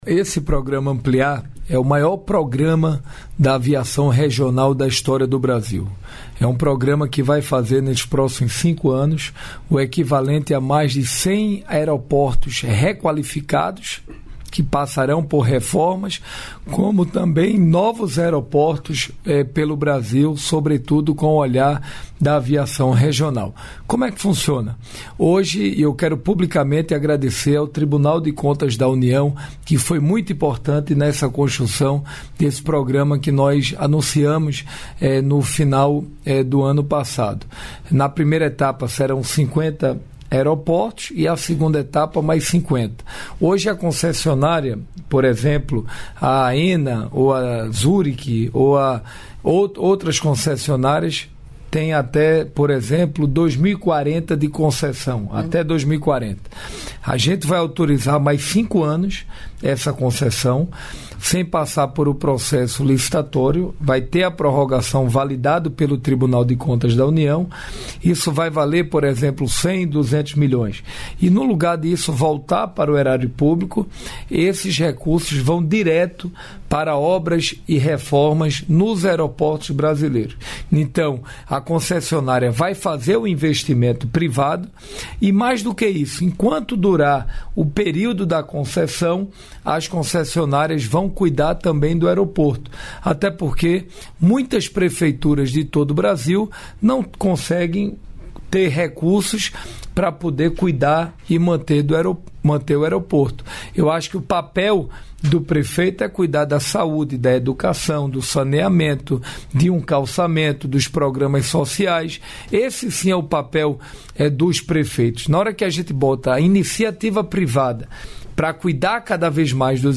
Trecho da participação do ministro de Portos e Aeroportos, Silvio Costa Filho, no programa "Bom Dia, Ministro" desta quinta-feira (06), nos estúdios da EBC, em Brasília.